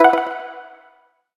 menu-play-click.ogg